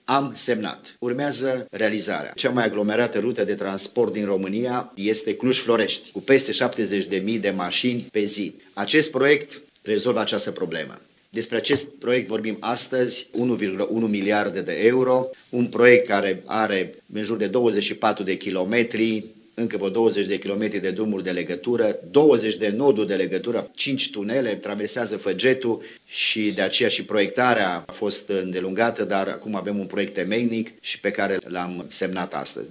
Contractul pentru proiectarea și execuția Tronsonului 2 al Centurii Metropolitane a Clujului a fost semnat luni, 12 mai, la București.
Prezent la semnare, primarul Emil Boc a precizat ca acest proiect de infrastructura rutiera va ajuta la fluidizarea traficului din Cluj-Napoca si din zona metropolitana a municipiului: